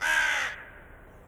croworraven1.wav